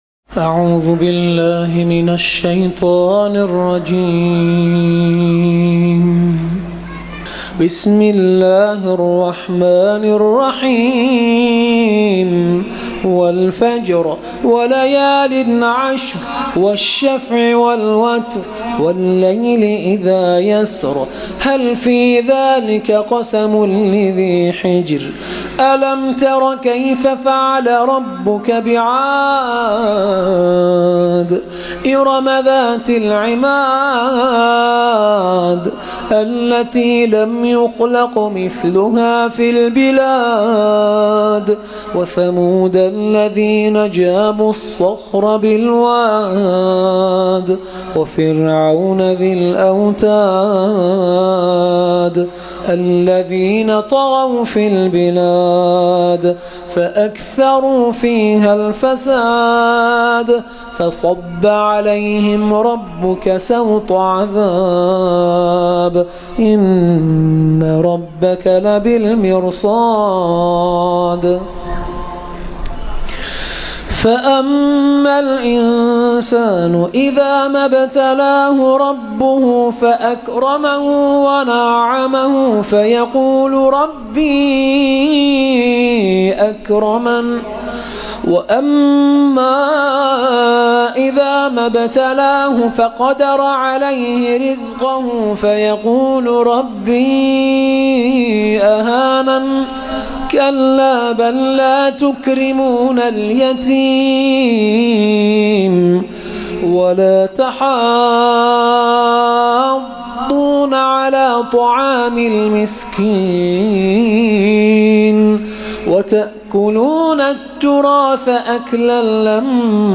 تکیه | ترتیل سوره فجر